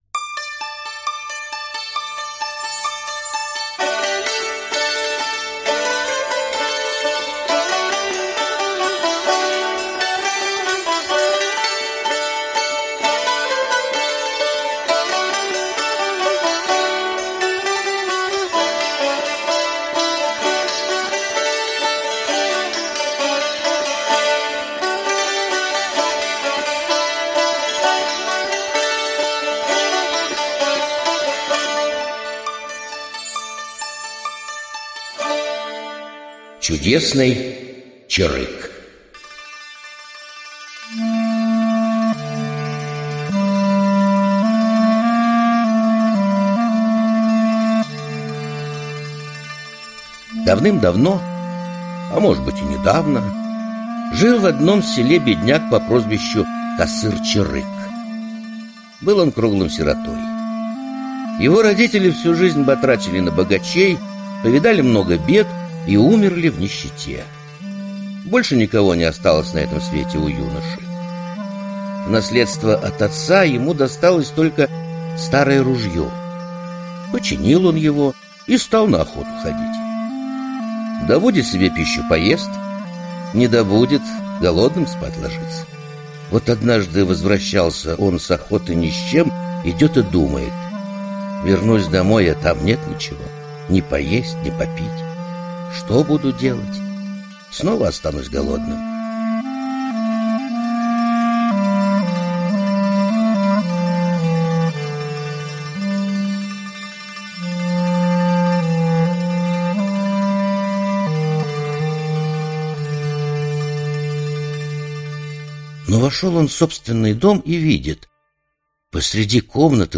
Аудиокнига Чудесный чарык. Крымско-татарские народные сказки | Библиотека аудиокниг